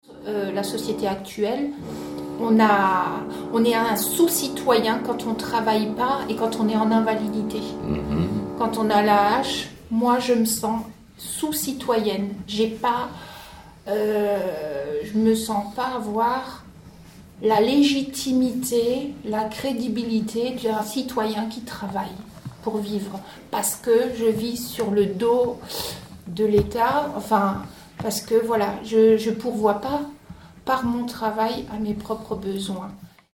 Handicap Psychique - Témoignages recueillis par le CNAHES Lorraine le 3 février 2017
Ensemble de Témoignages anonymisés de  » citoyens-usagers « , volontaires de l’association Espoir 54, en situation de fragilité psychique.